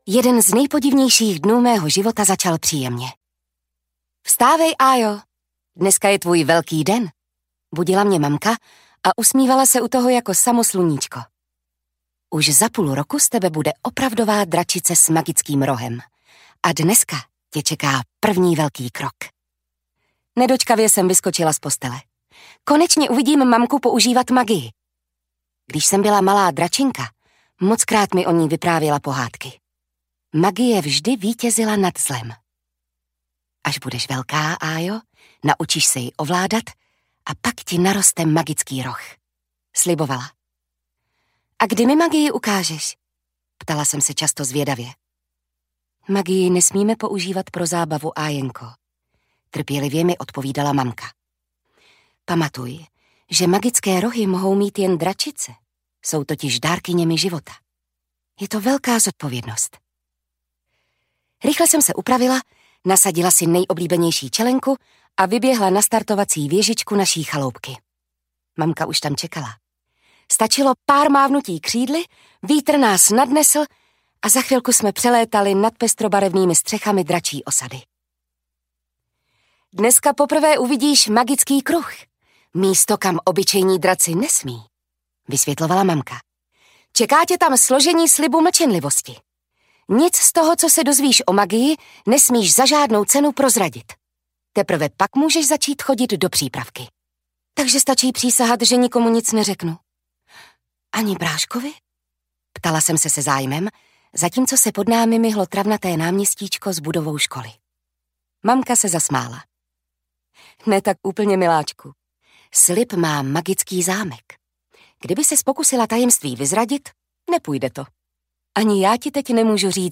Ukázka z knihy
kroniky-beskydskych-draku-tajemstvi-ztracene-kroniky-audiokniha